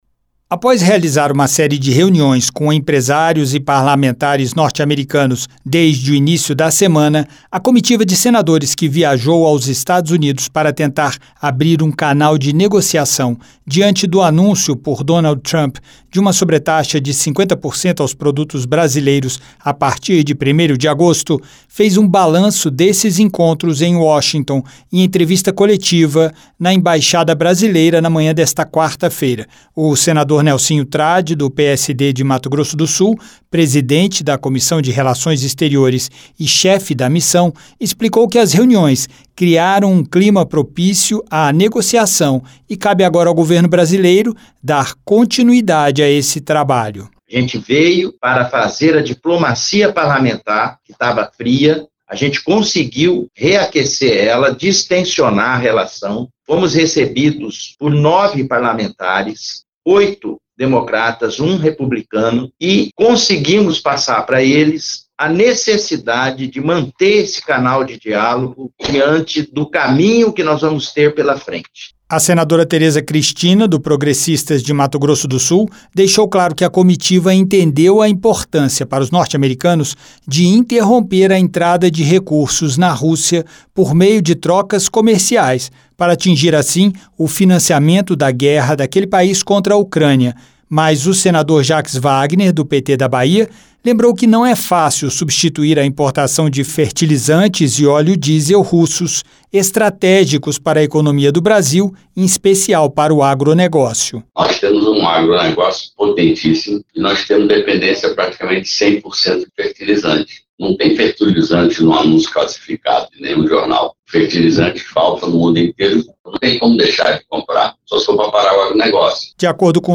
Em entrevista coletiva na Embaixada o Brasil em Washington DC nesta quarta-feira (30), a comitiva de senadores que viajou aos EUA para abrir um canal de negociação diante do anúncio, pelo presidente estadunidense Donald Trump, de uma sobretaxa de 50% aos produtos brasileiros, fez um balanço das reuniões com parlamentares e empresários norte-americanos.